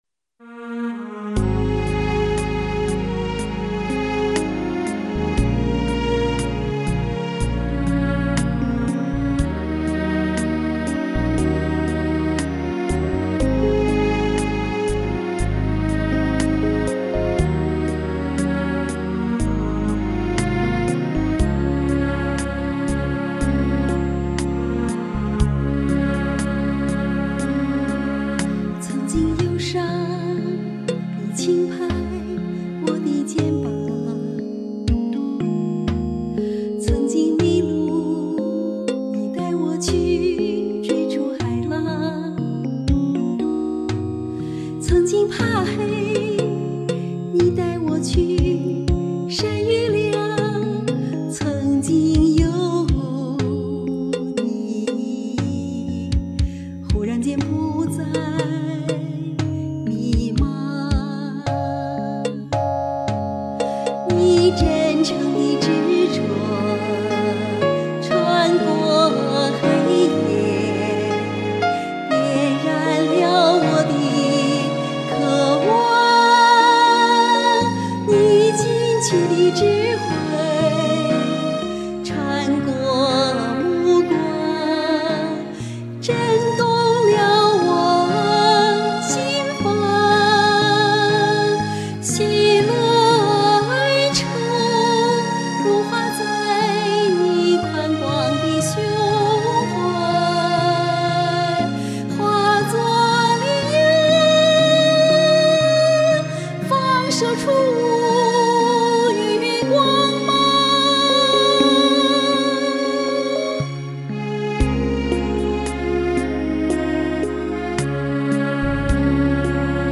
【曾经】-原创歌曲
曲子平缓、简约，优美，像是心底的耳语。